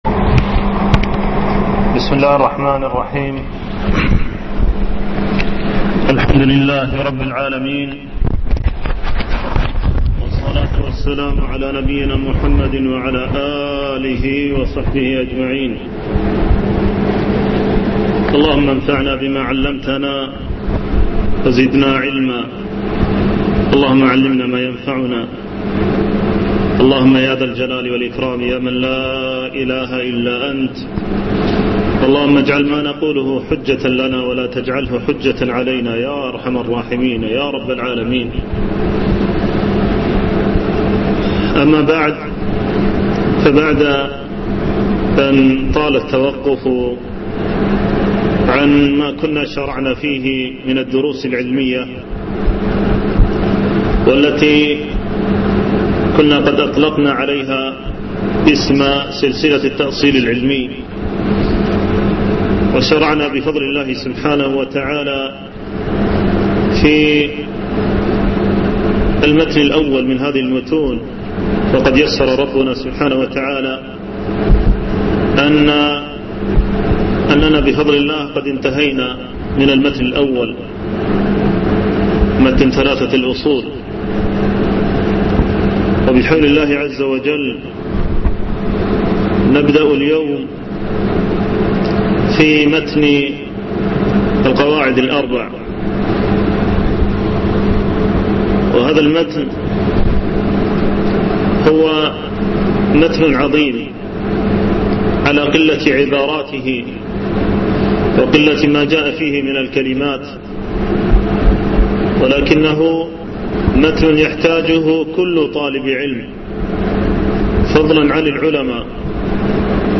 شرح القواعد الأربع - الدرس الأول